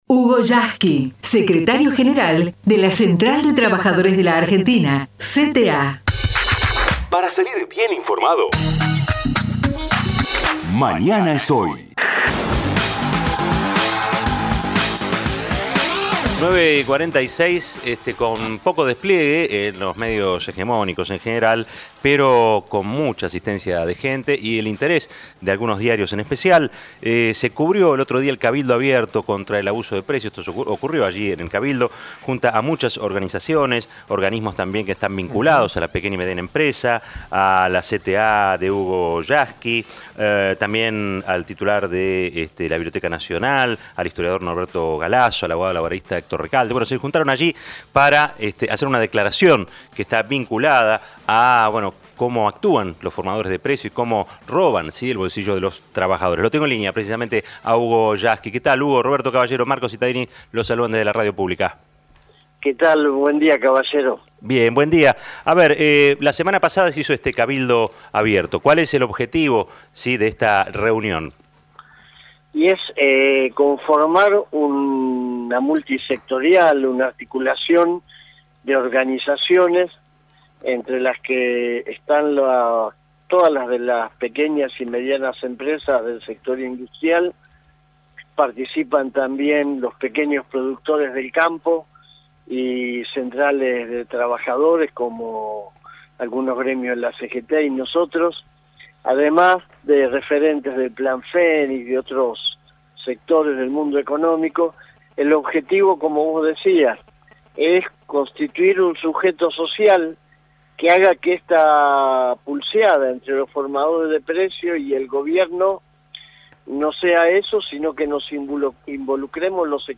El secretario General de la CTA entrevistado en el programa "Mañana es hoy" de la Radio Pública